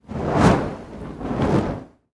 Звук движения от точки А до точки Б